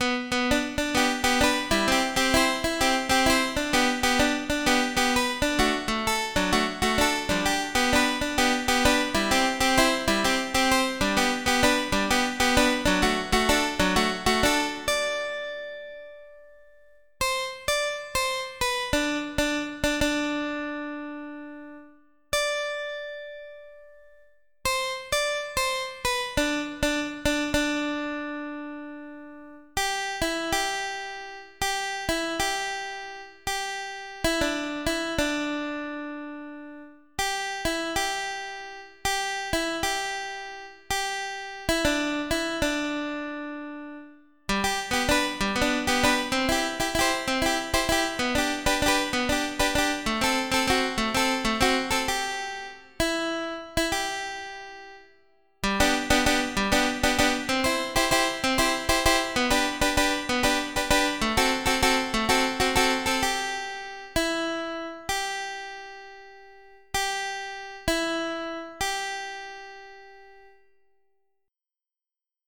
Extended MIDI